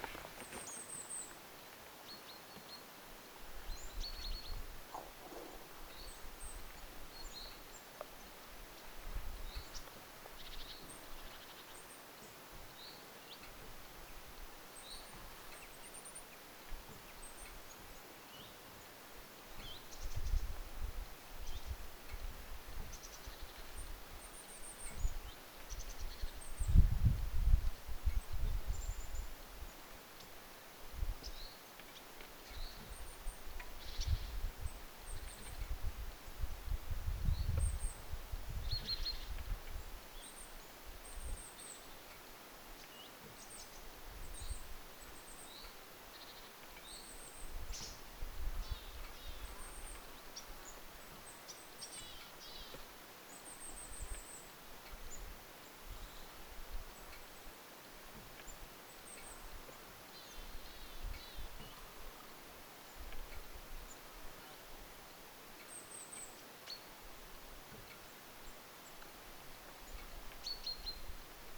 pikkulintuparvi
pikkulintuparvi.mp3